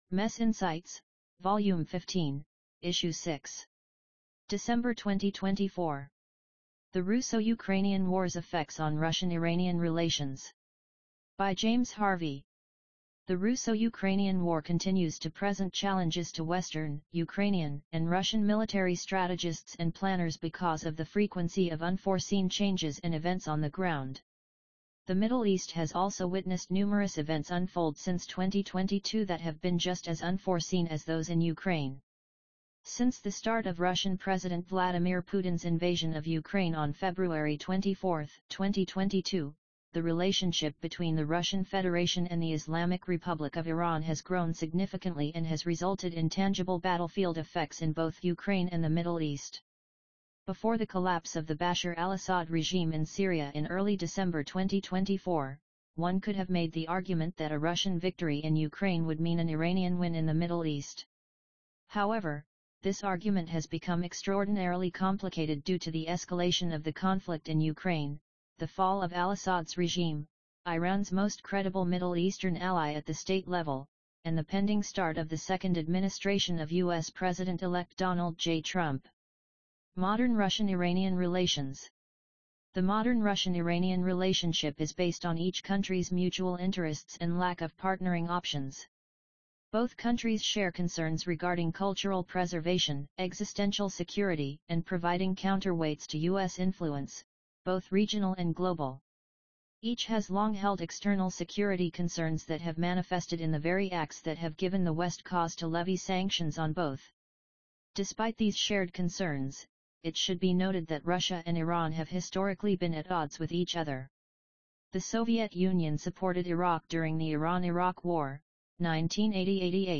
MES insights_15_6_AUDIOBOOK.mp3